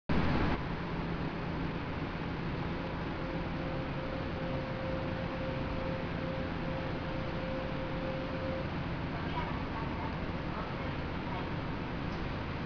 ・16000系 車載発車放送（更新前）
【JR線内】（12秒：70.8KB）
JR線内で流れる場合はブザー後の自動放送がJR仕様になっていたのが特徴と言えそうです。